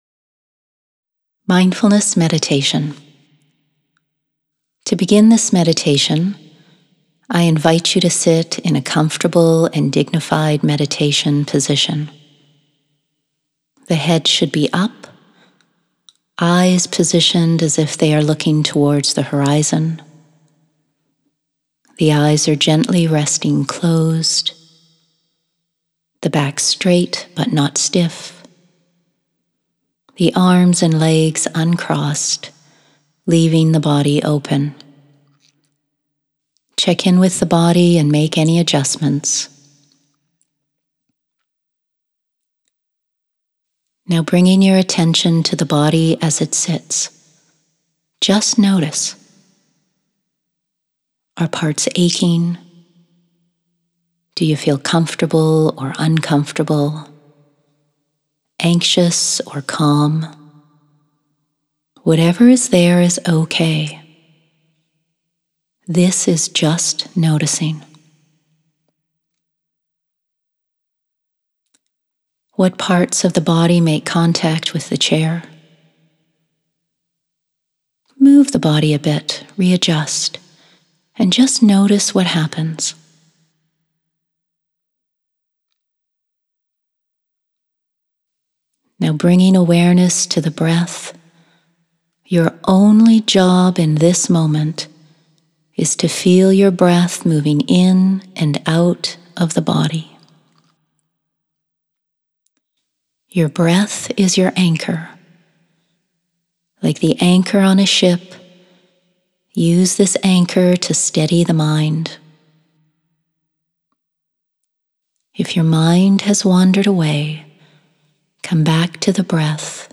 1.-Mindfulness-Meditation.m4a